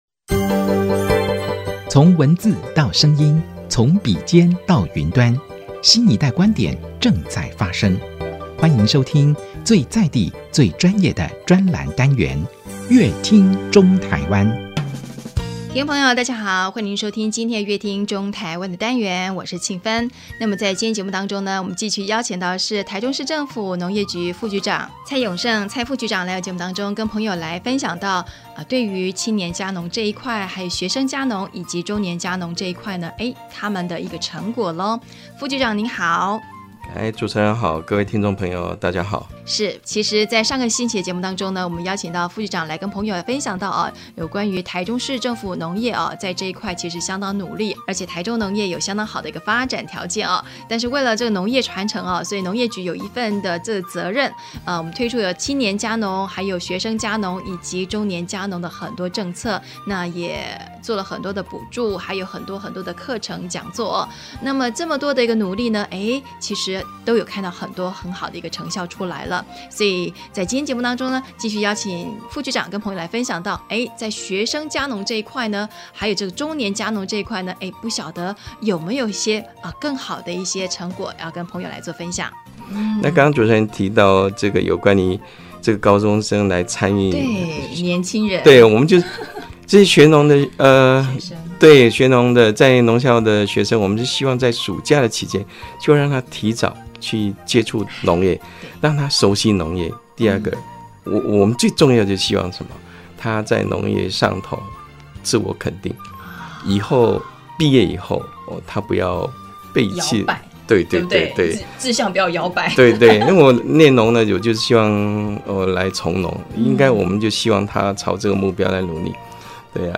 本集來賓：臺中市政府農業局蔡勇勝副局長 本集主題：中青壯年加農 2018國際花博展成果 本集內容： 為農業傳承